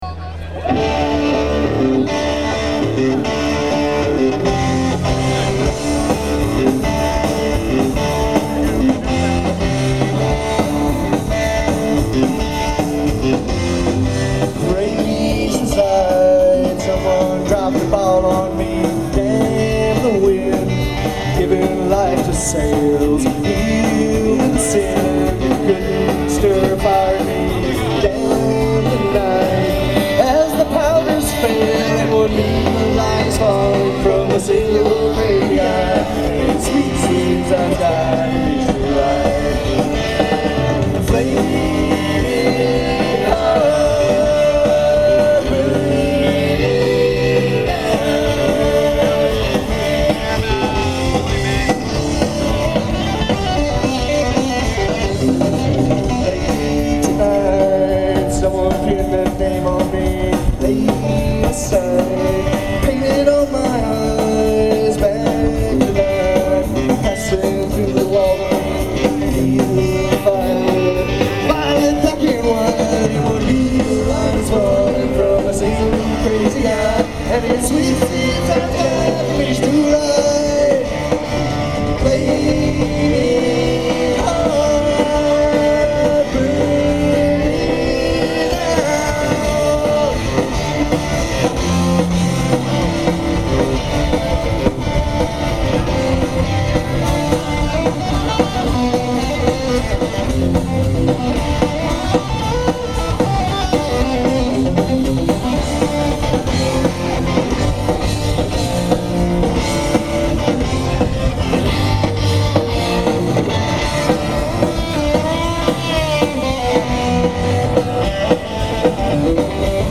of drunk crowd idiocy, a bit of crowd singalong.
who just wanted loud rock music.